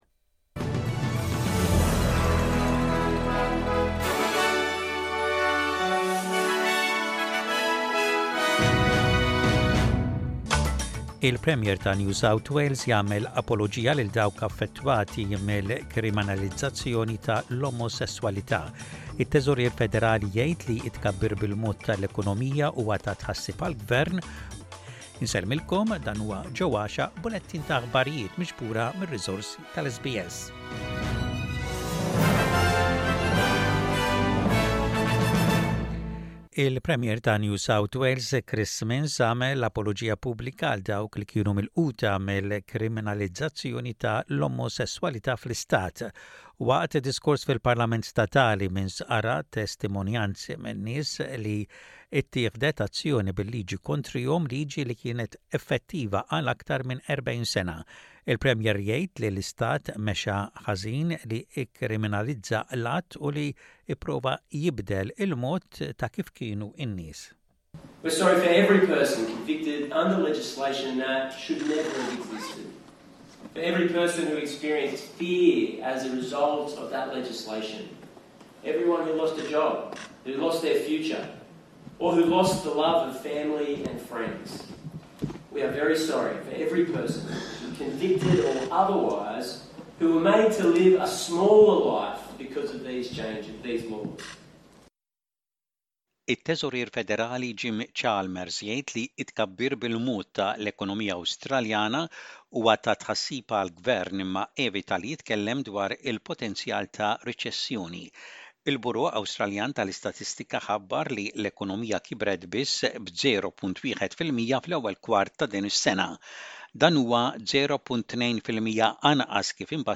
SBS Radio | Maltese News: 07.06.24